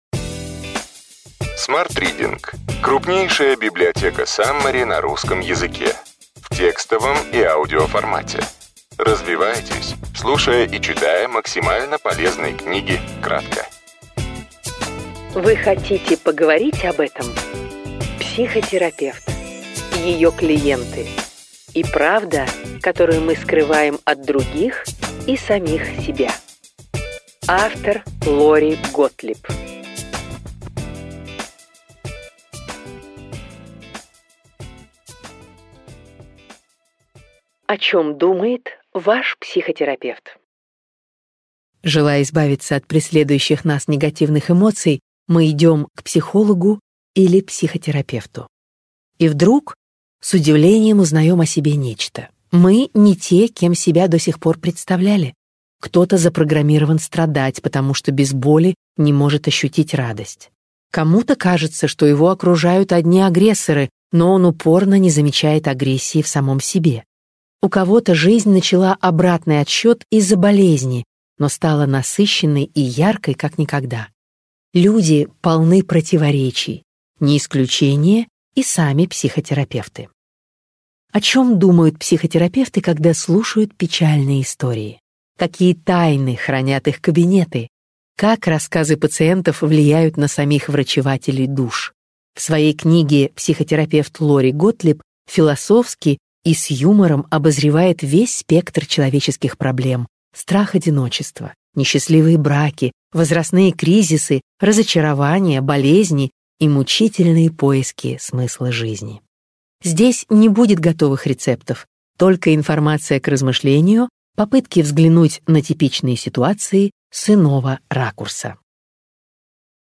Аудиокнига Вы хотите поговорить об этом? Психотерапевт. Ее клиенты. И правда, которую мы скрываем от других и самих себя. Лори Готтлиб. Саммари | Библиотека аудиокниг